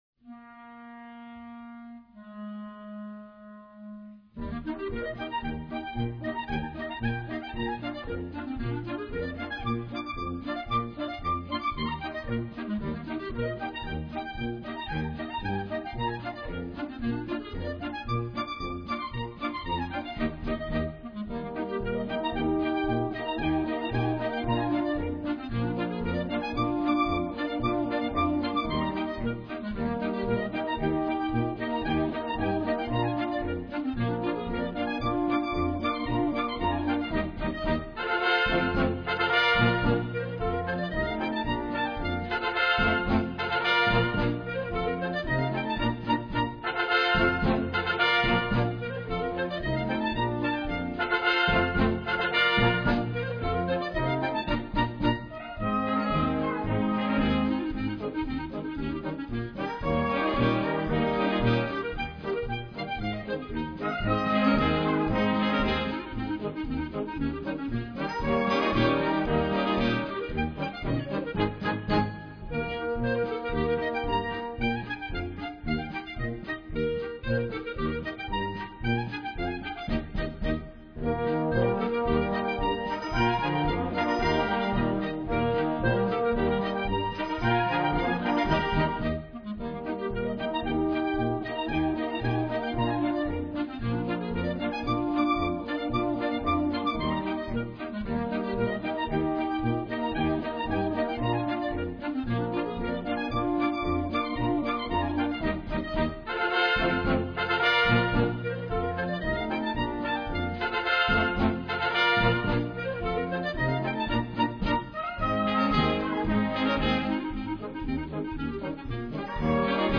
Solostück für Klarinette
Blasorchester